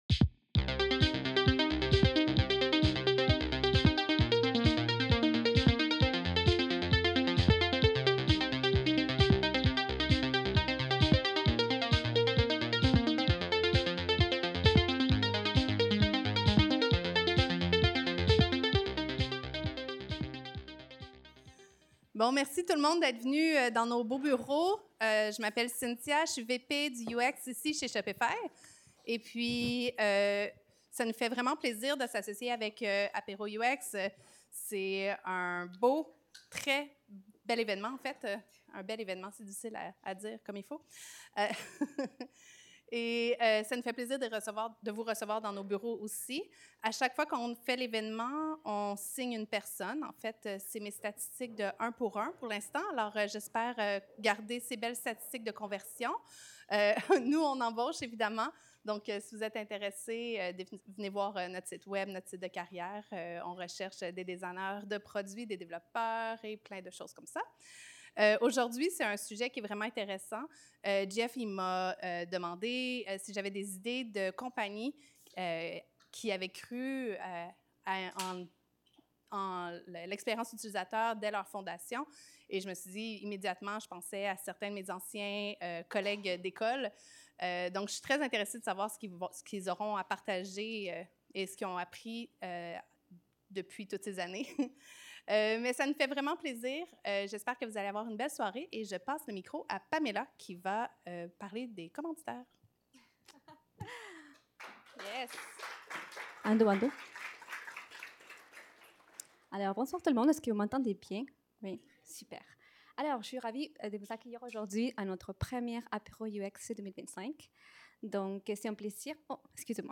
Evénement du 20 septembre 2023: Sobriété numérique et éco-conception En tant que concepteurs numériques : designers, développeurs, product owner… Vous vous posez certainement la questions suivante !